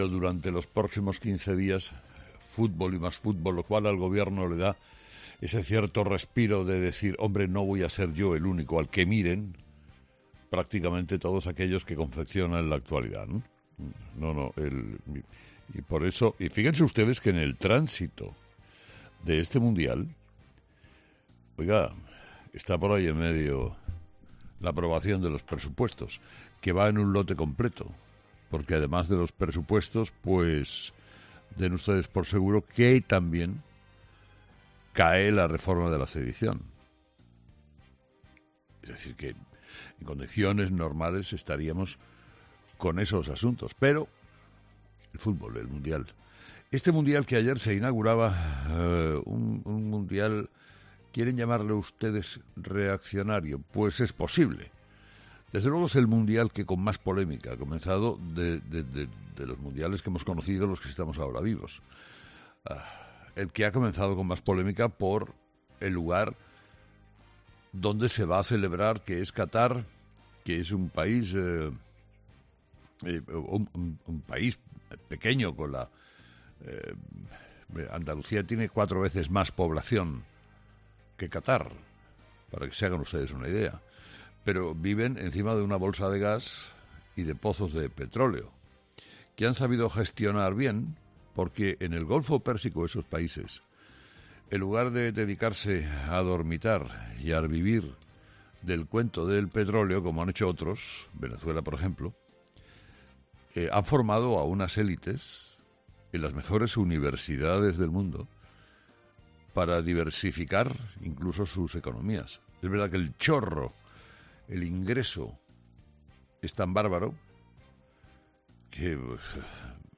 Carlos Herrera ha querido comenzar el programa de este lunes haciendo un resumen de lo que ha dado de sí el arranque del Mundial de Qatar.